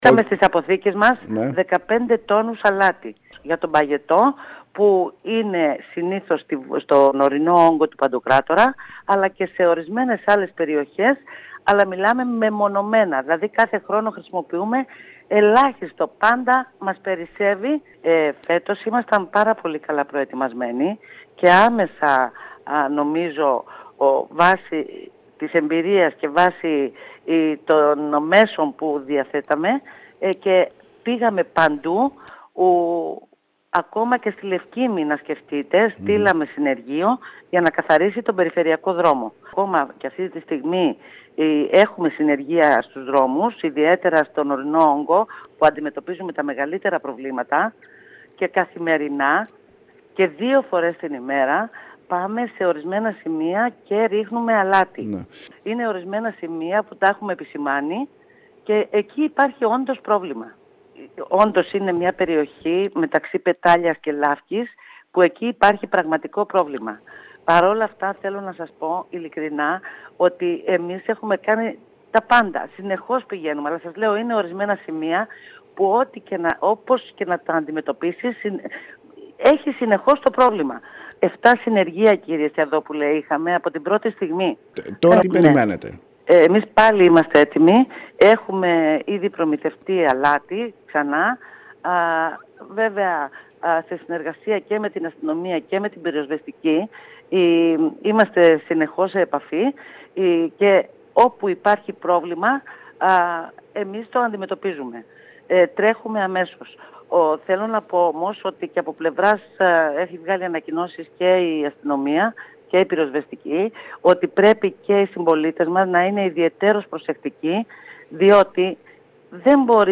Θετικός υπήρξε ο απολογισμός της υπηρεσίας πολιτικής προστασίας για την προετοιμασία του νησιού κατά τη διάρκεια των έντονων φαινομένων που ζήσαμε τις τελευταίες ημέρες.  Μιλώντας στην ΕΡΤ Κέρκυρας, η Αντιπεριφερειάρχης Νικολέττα Πανδή ανέφερε ότι οι υπηρεσίες βρίσκονται σε εγρήγορση για το νέο κύμα έντονων καιρικών φαινομένων.